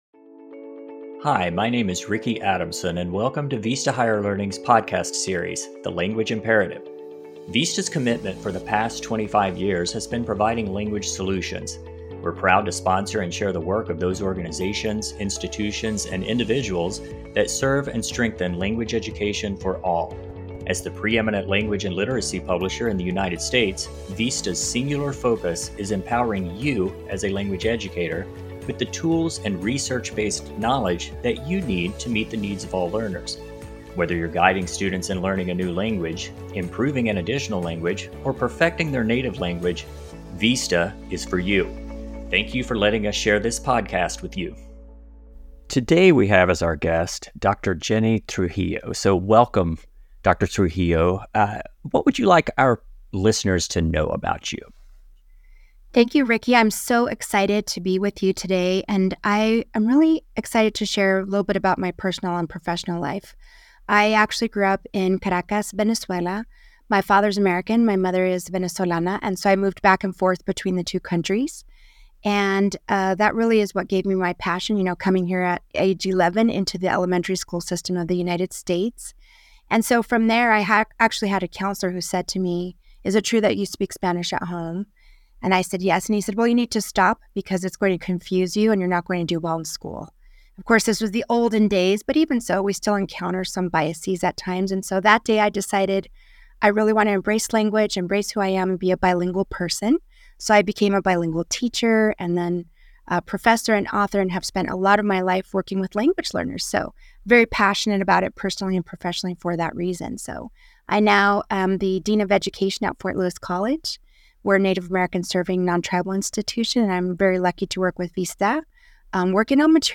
Tune into Vista’s new podcast series, The Language Imperative, for insightful conversations with award-winning language educators—from the intimacy of hands-on workshops to the development of powerful systems at the state and local levels—all with an eye towards national transformation and empowerment.